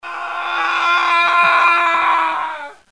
Âm thanh tiếng Hét (Giọng đàn ông)
Thể loại: Tiếng con người
Description: Âm thanh tiếng Hét Giọng đàn ông, tiếng thét, tiếng gào, tiếng la hét, tiếng rống, tiếng kêu thất thanh, tiếng quát, tiếng hô hoán, tiếng gầm, giọng nam gầm rú, tiếng rú hoảng loạn giọng nam giới, giọng nam, chàng trai.
tieng-het-giong-dan-ong-www_tiengdong_com.mp3